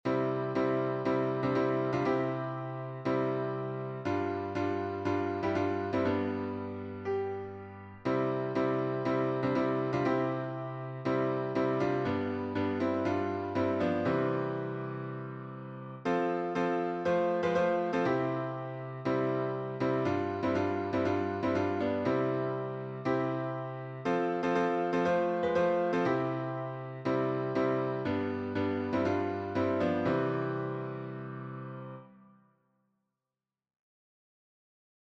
A traditional American hymn, and both music and text are attributed to Robert Lowry (1826–1899), a Baptist preacher of some brilliance.